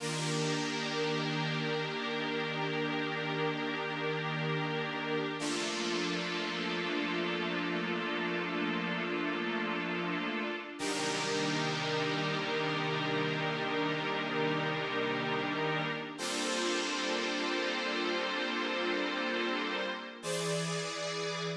03 pad A.wav